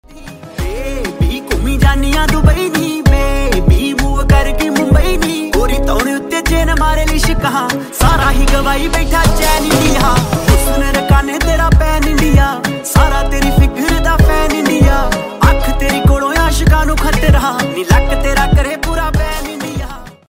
Latest Punjabi hit ringtone download.